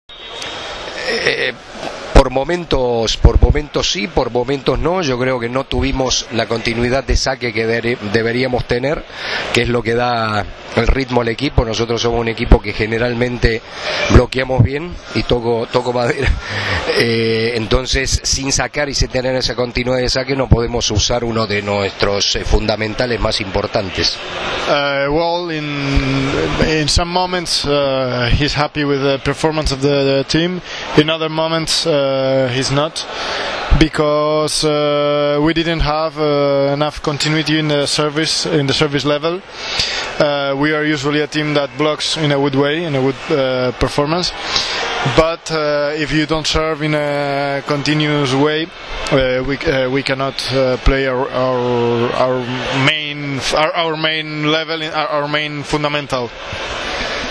IZJAVA
SA PREVODOM